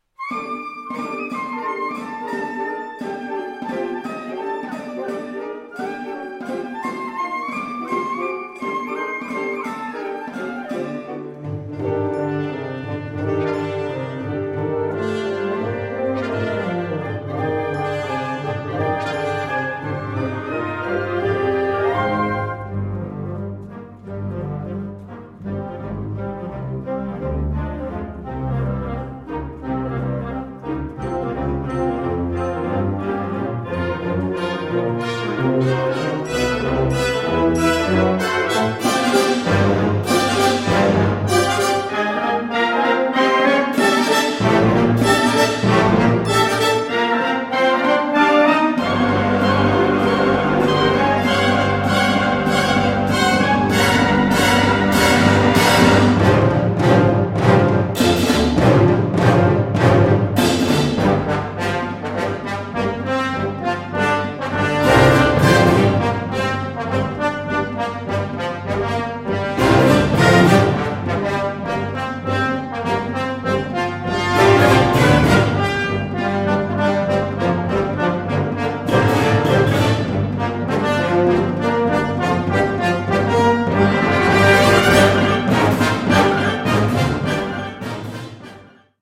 Kategorie Blasorchester/HaFaBra
Allegretto scherzando